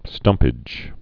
(stŭmpĭj)